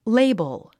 発音
léibəl　レイボゥ